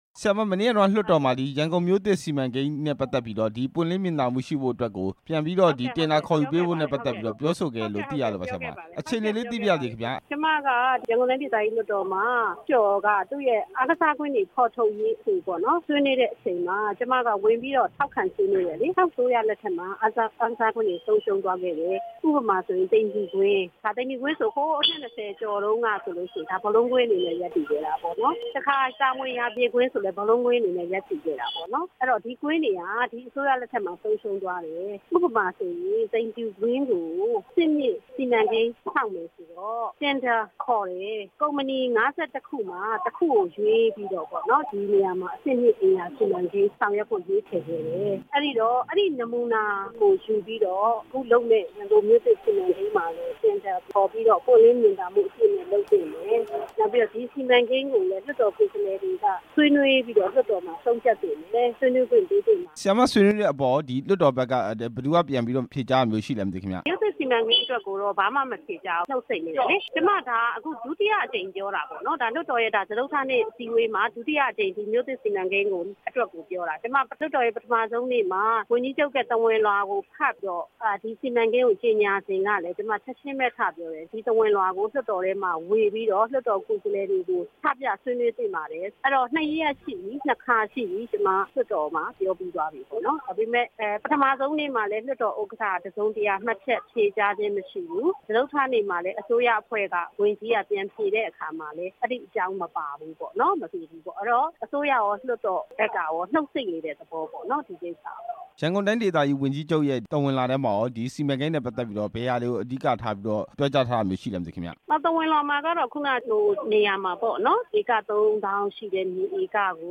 ရန်ကုန်မြို့သစ် စီမံကိန်း တင်ဒါခေါ်ယူမှု မေးမြန်းချက်